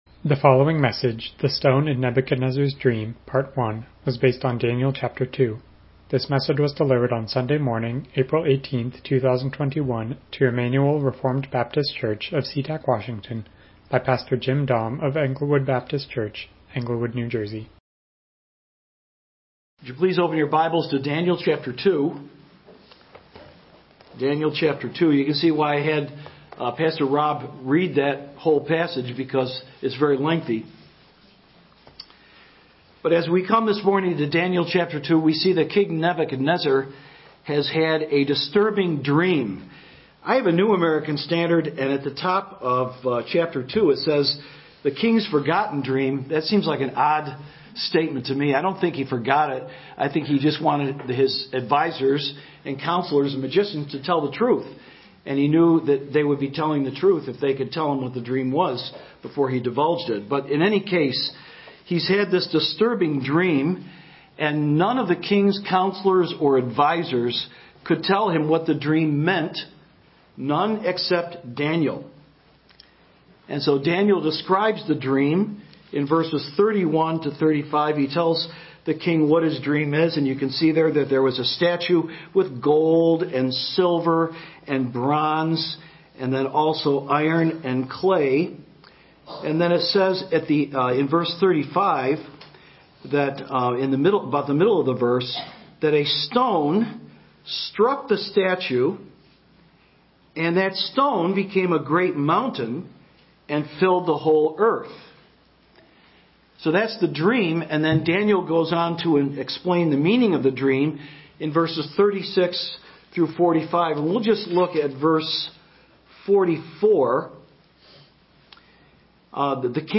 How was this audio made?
Passage: Daniel 2 Service Type: Morning Worship « The Mediatorial Work of Christ The Stone in Nebuchadnezzar’s Dream